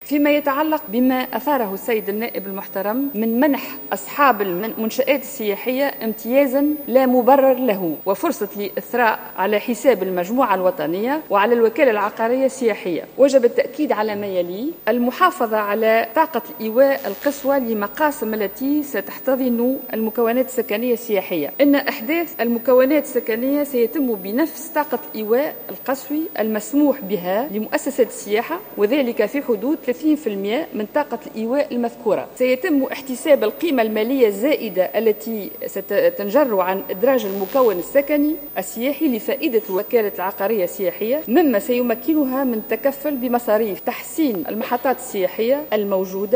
وأكدت في جلسة عامة للاستماع إلى الحكومة، أن الوزارة تحرص على المحافظة على طاقة الايواء القصوى للمقاسم التي ستحتضن المكونات السكنية السياحية، وأضافت أن إحداث المكونات السياحية يتم بحسب طاقة الإيواء القصوى نفسها والمسموح بها للمؤسسات السياحية وفي حدود 30 بالمائة من طاقة الإيواء، بحسب توضيحها.